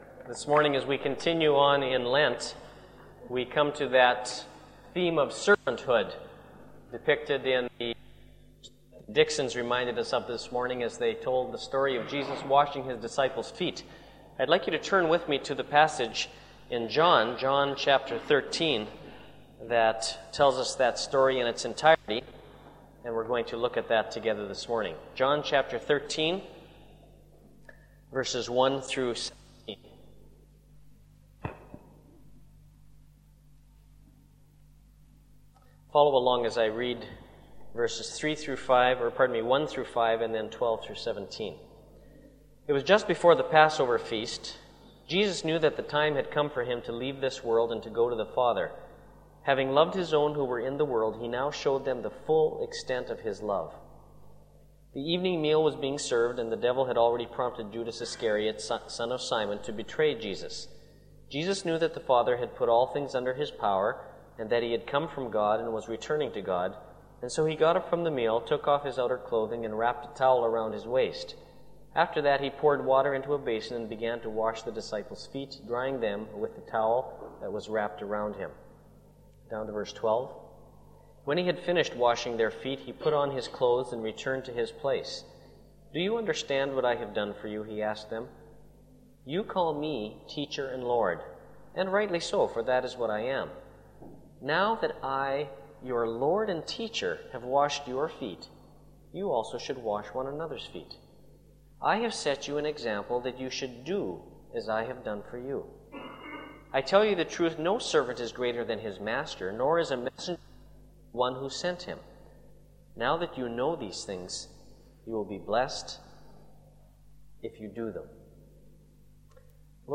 *Note: The exact date for this sermon is unknown.